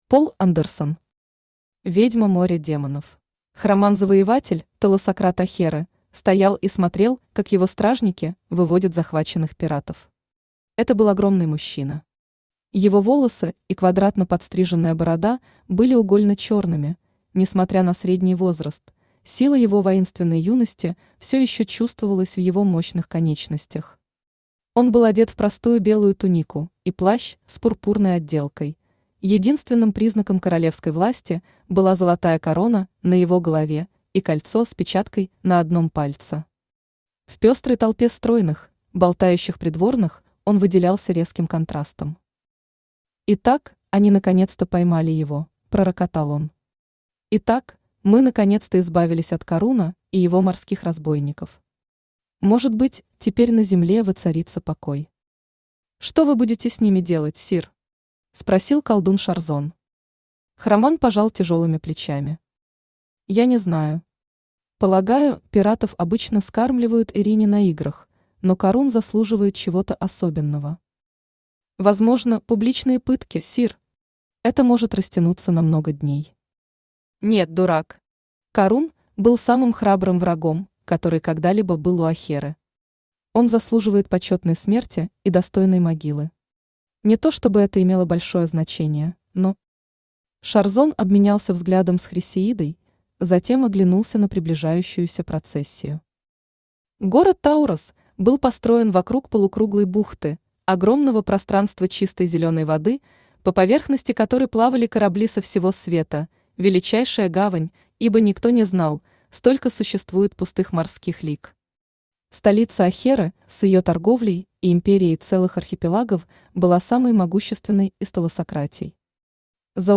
Аудиокнига Ведьма моря Демонов | Библиотека аудиокниг
Aудиокнига Ведьма моря Демонов Автор Пол Андерсон Читает аудиокнигу Авточтец ЛитРес.